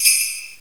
silence.ogg